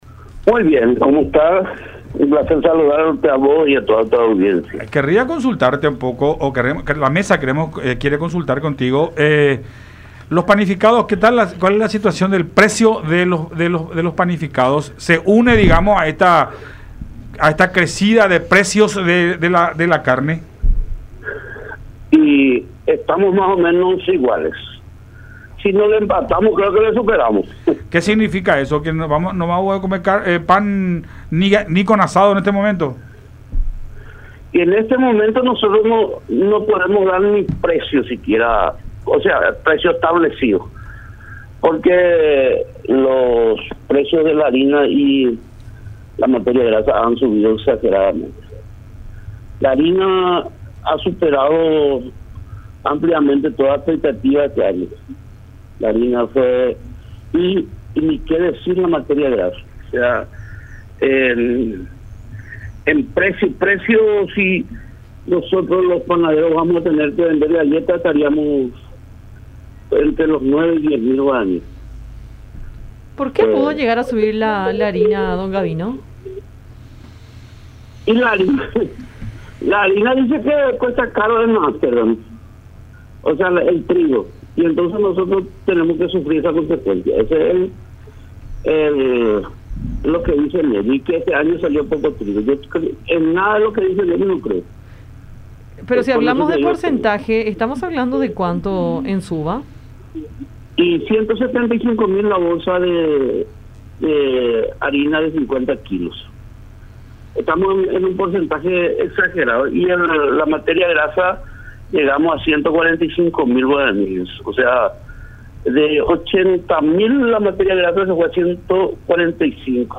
en charla con Enfoque 800 por La Unión.